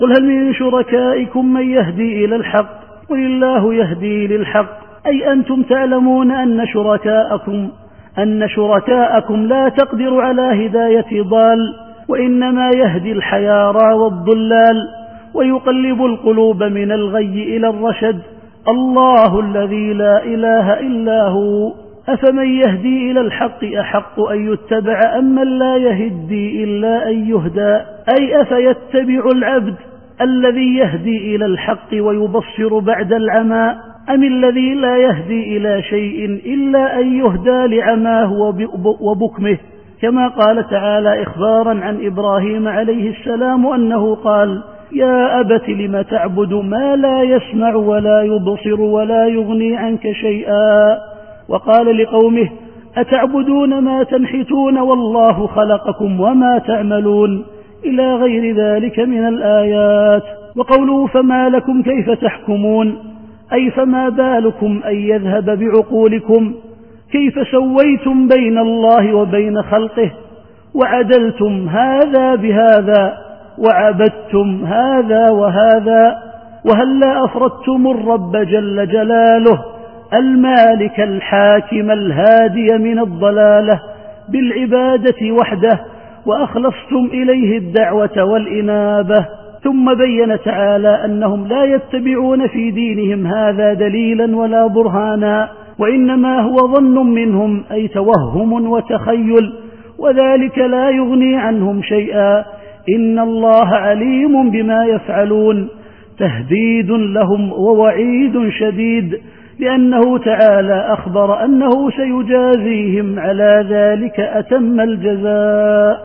التفسير الصوتي [يونس / 35]